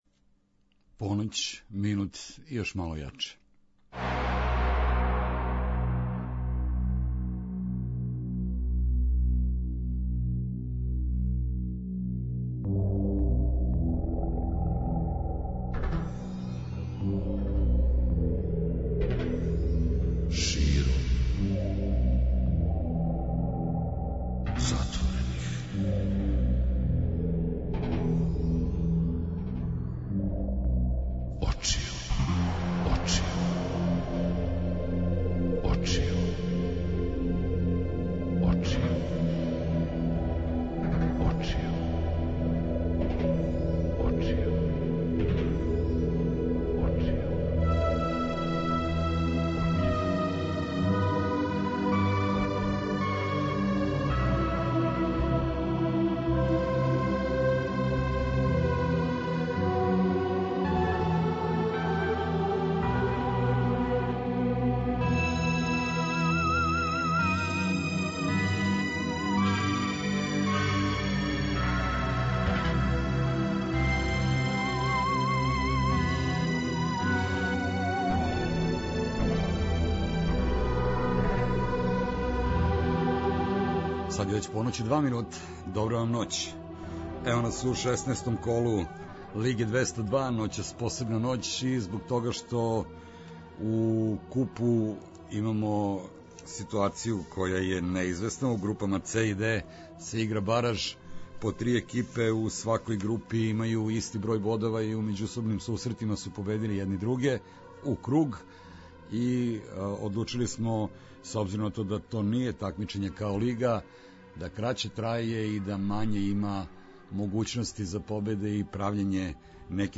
Спој добре рок музике, спортског узбуђења и навијачких страсти.